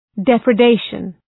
{,deprə’deıʃən}
depredation.mp3